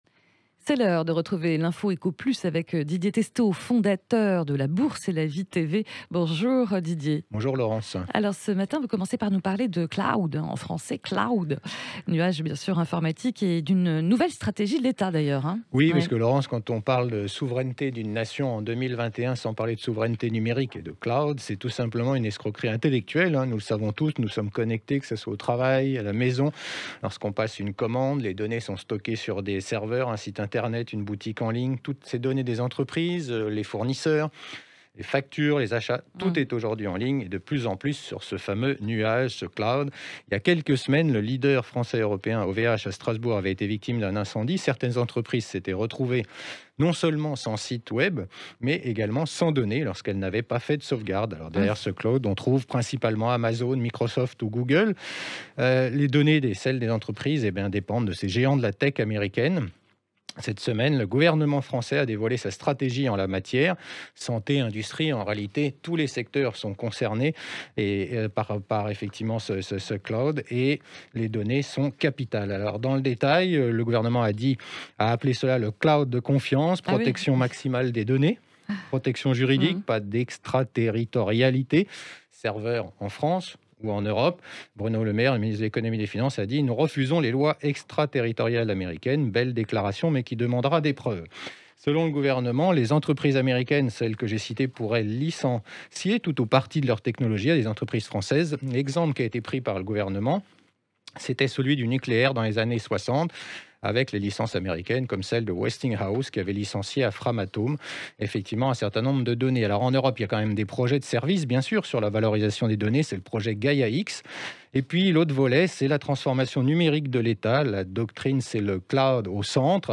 sur Sud Radio